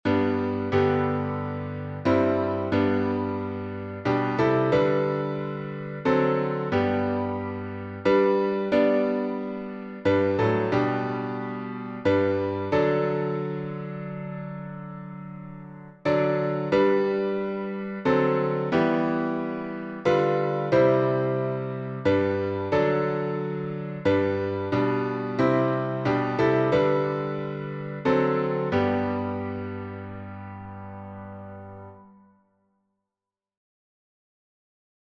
William H. Doane Key signature: G major (1 sharp) Time signature: 3/4 Meter: 8.6.8.6.(C.M.) Public Domain Organ Performance at Hymns Without Words 1.